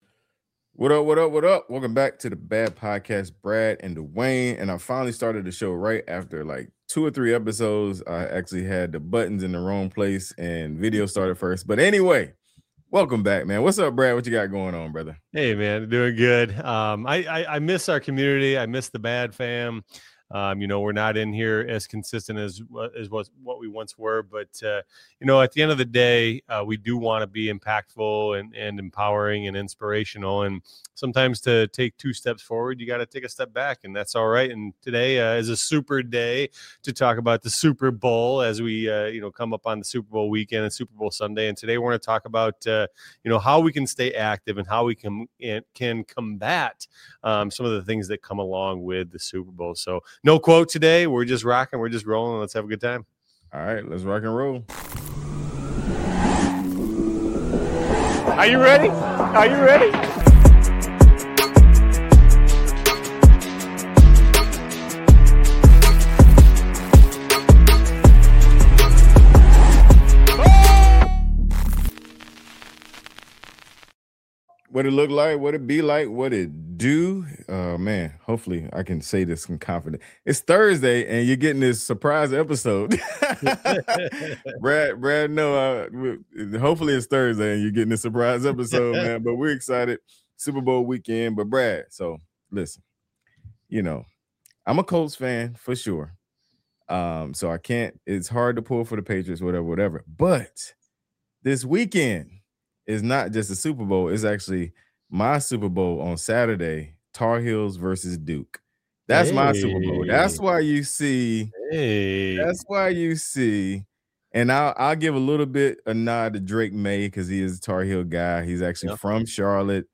Listen in for honest conversation, practical coaching, and motivation you can actually apply.